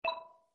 notification-bubble.mp3